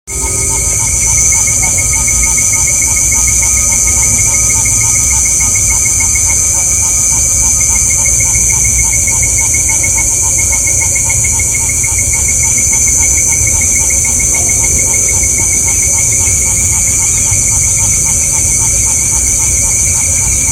Lesser Snouted Tree-frog (Scinax nasicus)
Class: Amphibia
Condition: Wild
Certainty: Recorded vocal
Scinax-nacisus.mp3